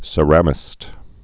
(sə-rămĭst, sĕrə-mĭst) or ce·ram·i·cist (sə-rămĭ-sĭst)